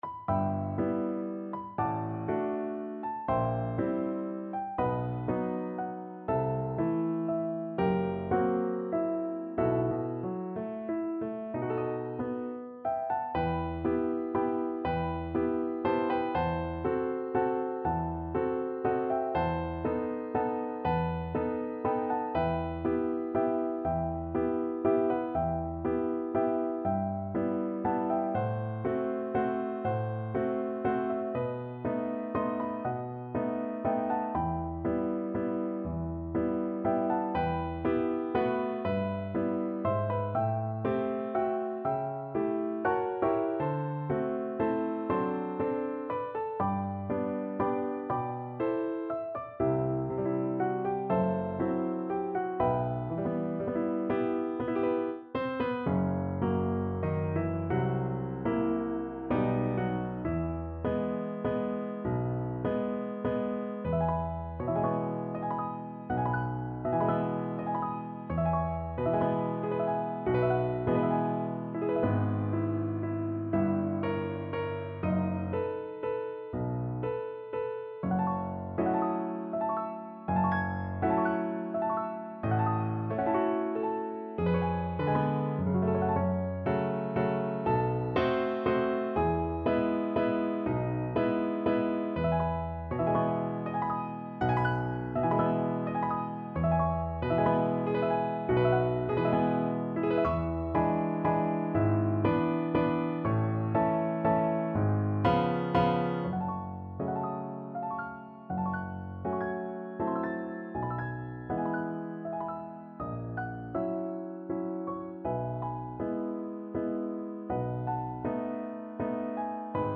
Piano version
kalman_wenn_es_abend_PNO.mp3